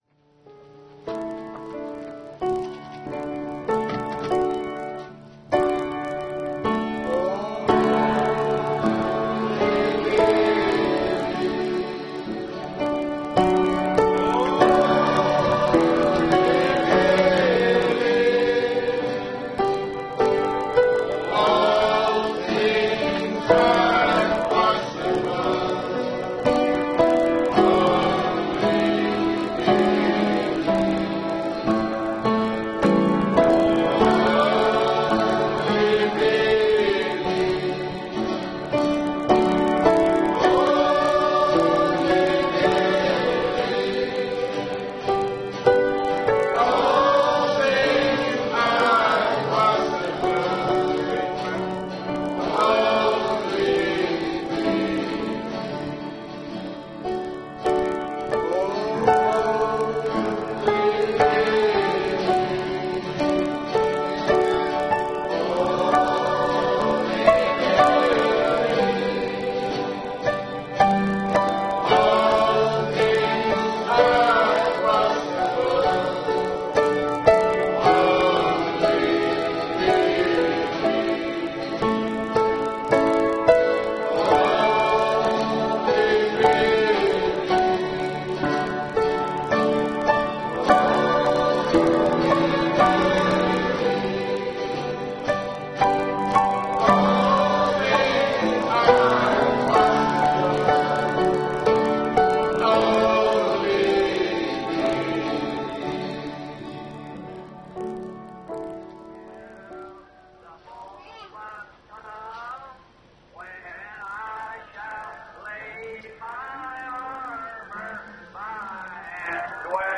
Vertaalde prediking "Believing God" door W.M. Branham te Branham Tabernacle, Jeffersonville, Indiana, USA, 's ochtends op zondag 24 februari 1952